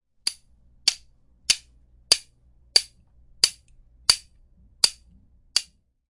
音频1 " 13 Martillo
描述：Foley Final Audio1 2018